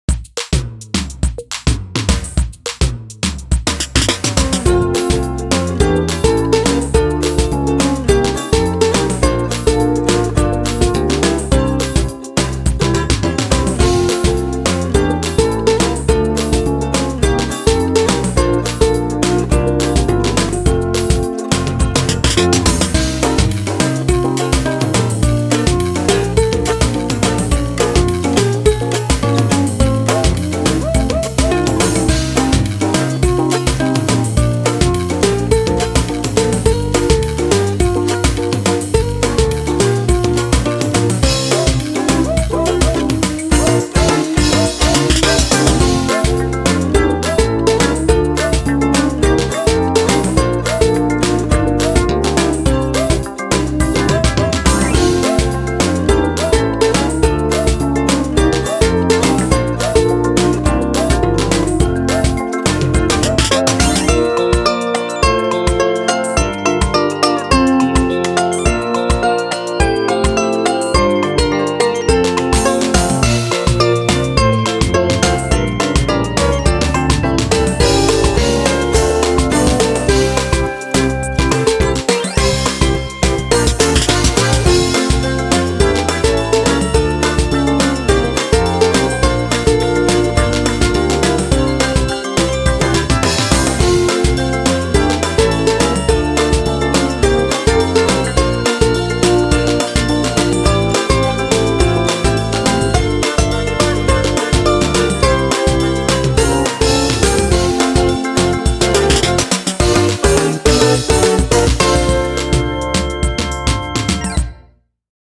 BPM210
Audio QualityPerfect (High Quality)
A nice and relaxing ukulele song, but fast and challenging!